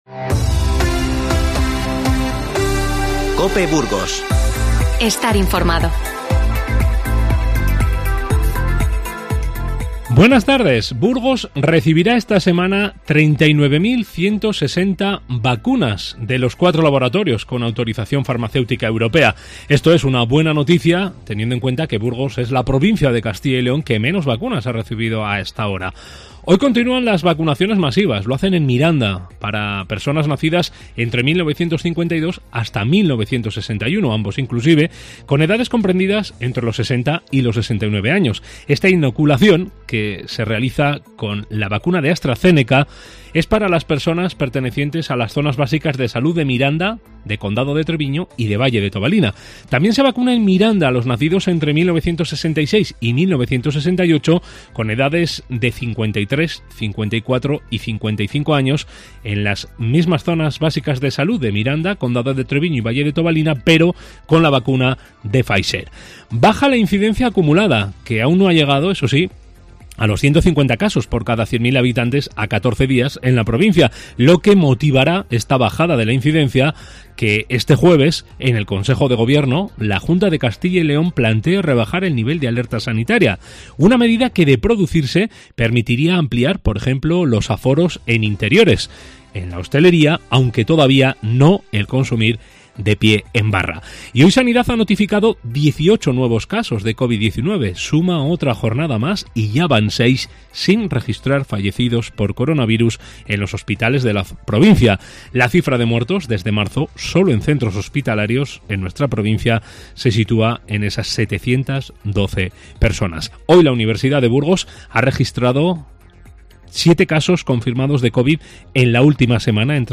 INFORMATIVO Mediodía 31-05-21